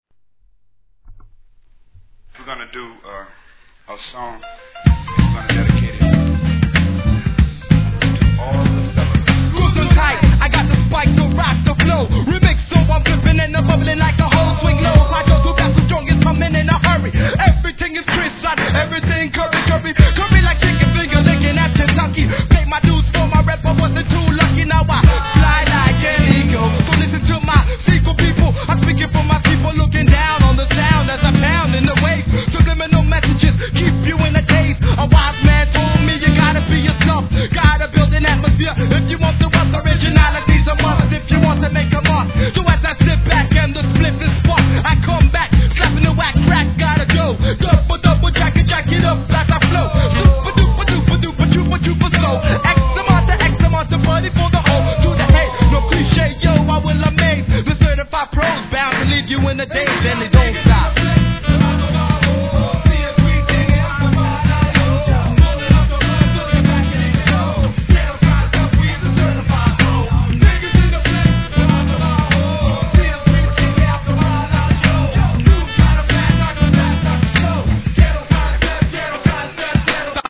HIP HOP/R&B
ド太いベースラインとそのサンプリング術はさすが!!